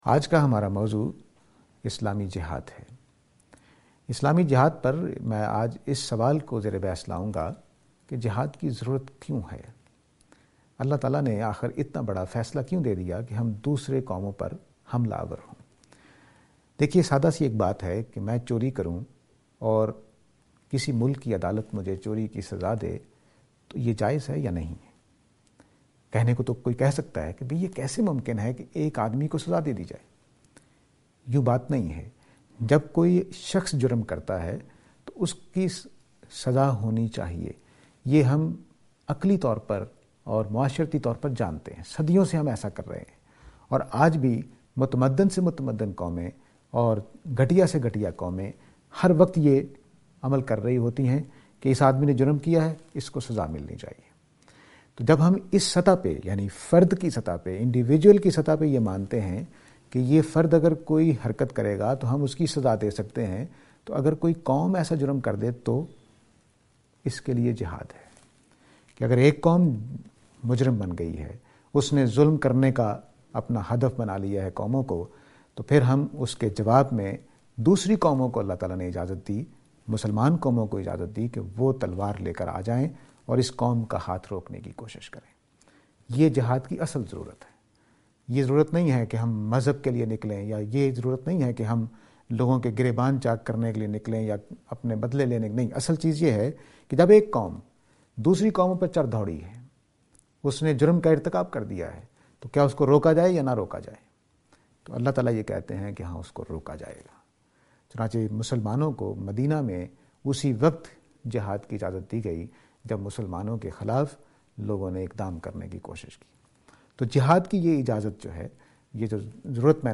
This lecture is and attempt to answer the question "Why do we need Jihad?".